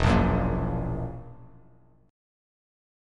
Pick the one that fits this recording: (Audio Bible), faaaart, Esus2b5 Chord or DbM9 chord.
Esus2b5 Chord